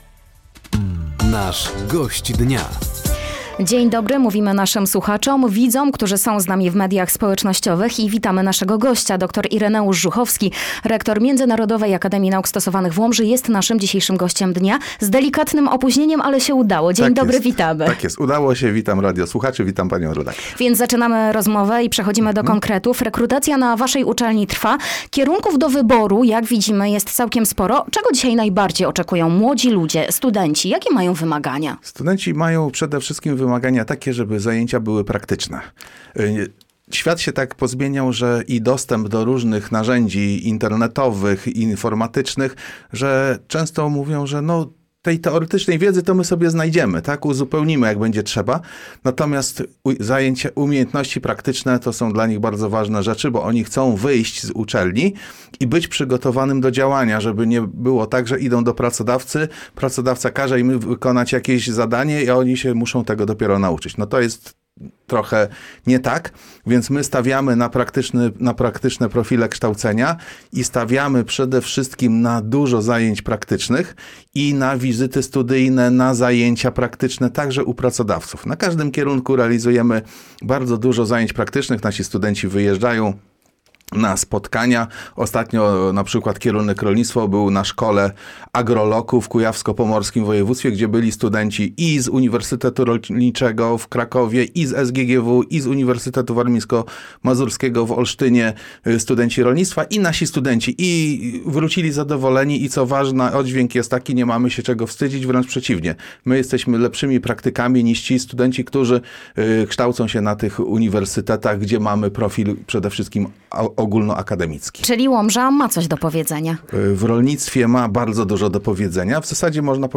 Rozpoczęta rekrutacja w Międzynarodowej Akademii Nauk Stosowanych w Łomży, przyszłość uczelni rolniczych w Polsce oraz Sztuczna Inteligencja w edukacji – to główne tematy rozmowy podczas audycji ,,Gość Dnia”.